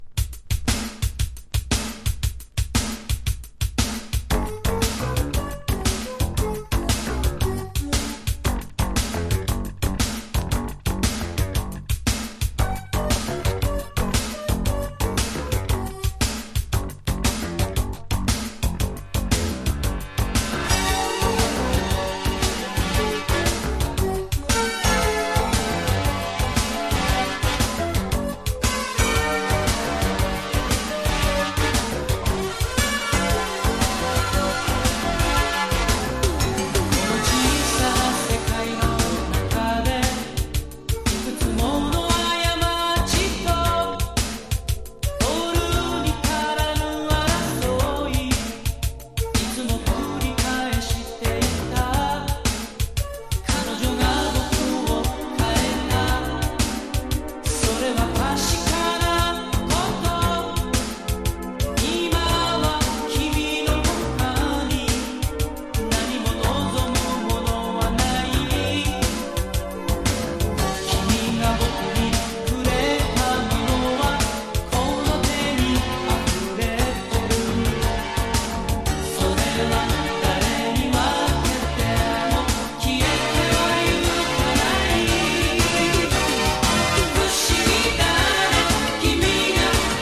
POP# CITY POP / AOR